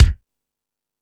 Lazer Drums(01).wav